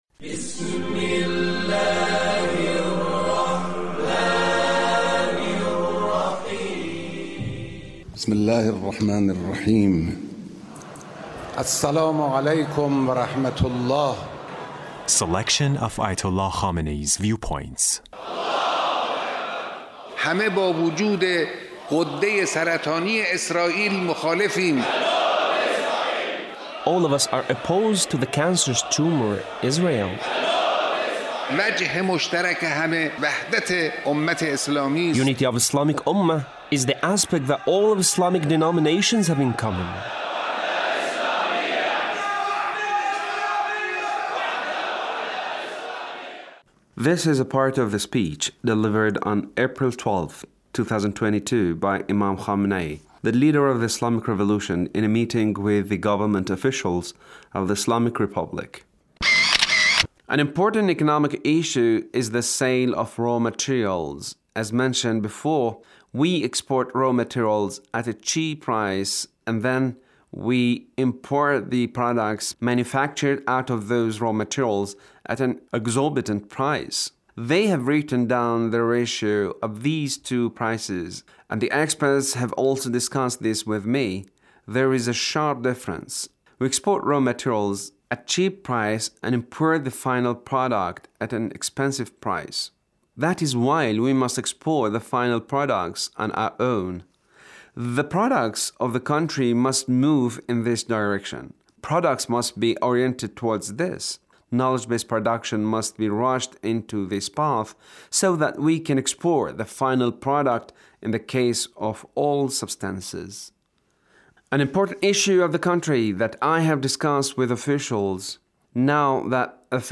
Leader's speech (1394)
The Leader's speech in a meeting with Government Officials